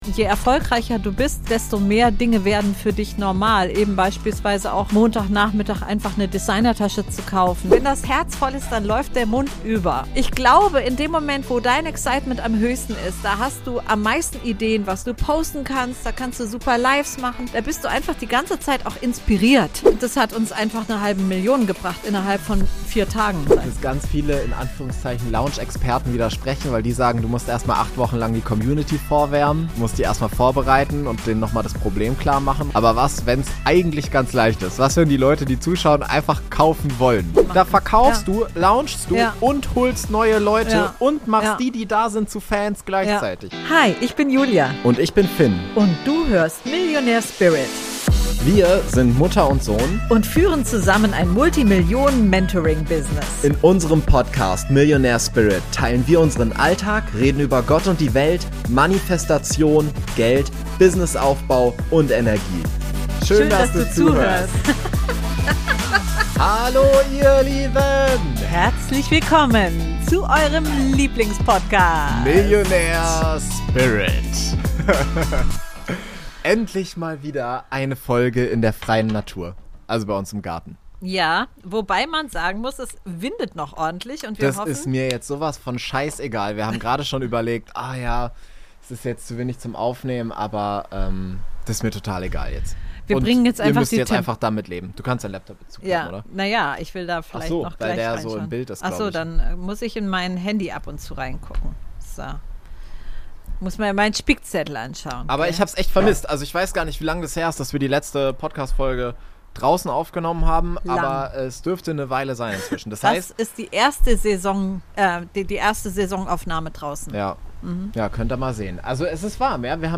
In der heutigen Podcast-Folge sprechen wir über all die Dinge auf die Du achten darfst, wenn Du erfolgreich launchen möchtest! Außerdem sitzen wir endlich mal wieder draußen